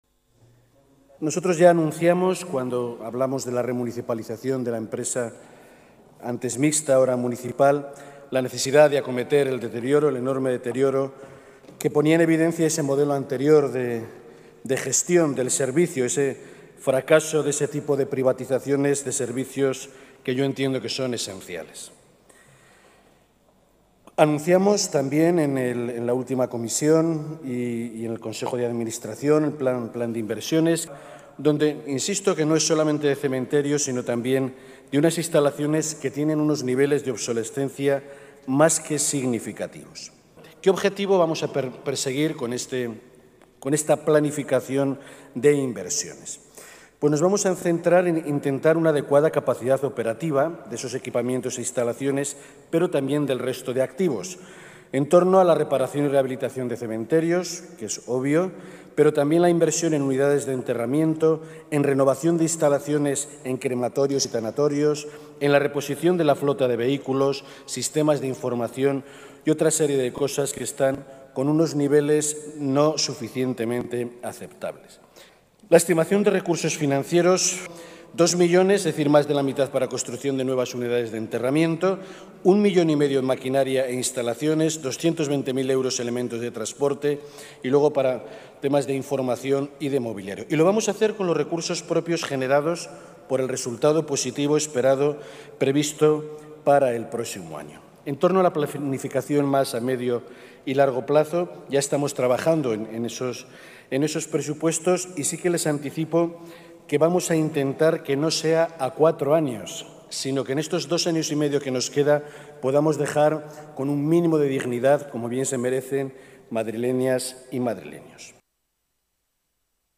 Barbero explica en el Pleno el Plan de Inversiones 2017-2019 para afrontar el deterioro de instalaciones, equipamientos y sistemas de información